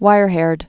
(wīrhârd)